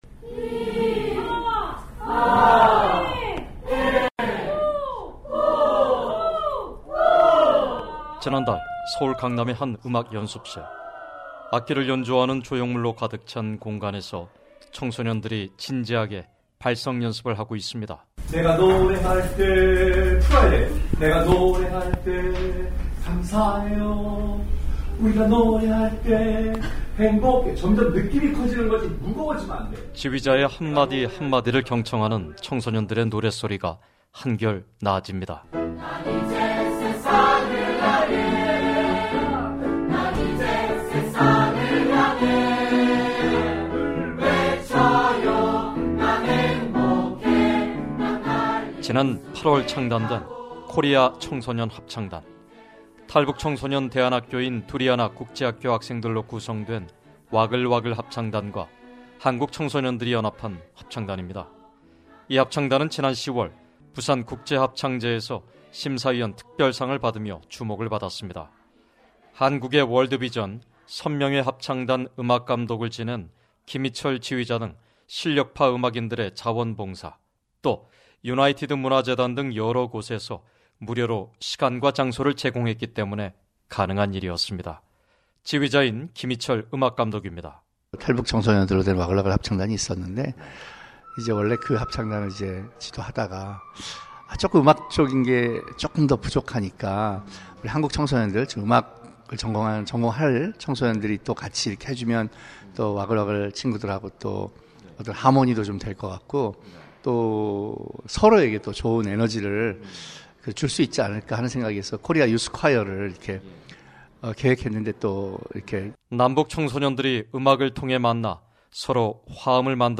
[특파원 리포트] 화음으로 희망을 노래하는 탈북·한국 청소년들 (1)